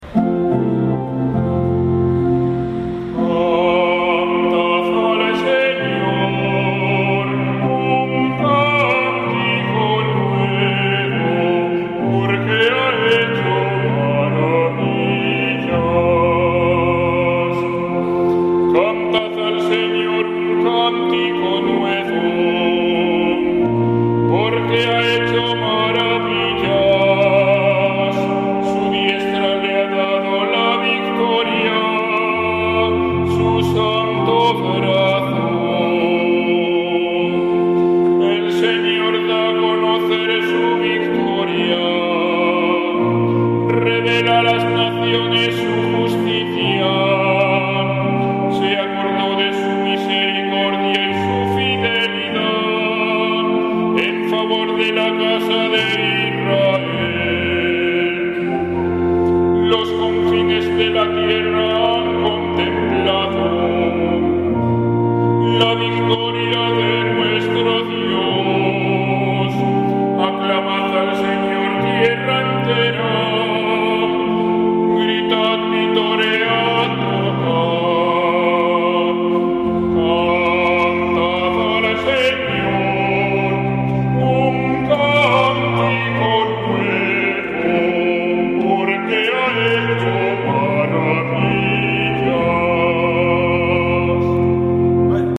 Salmo Responsorial 97/1-4